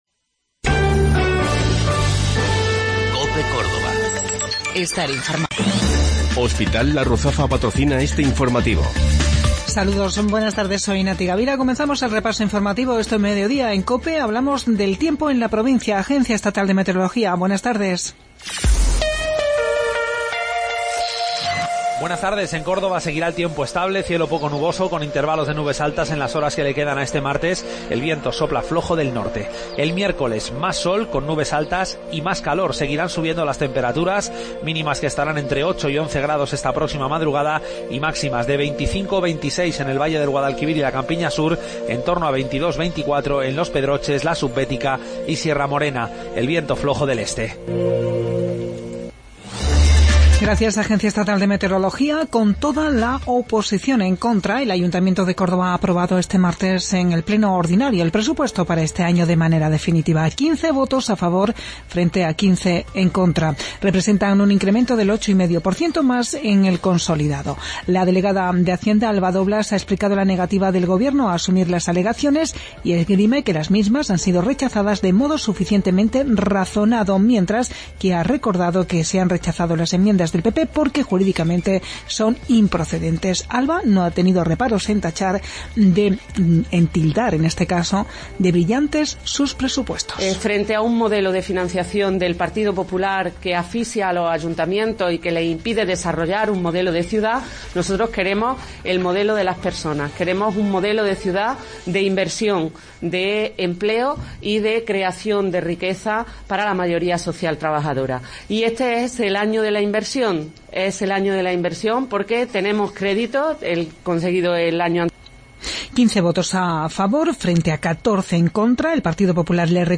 Mediodía en Cope. Informativo local 7 de Marzo 2017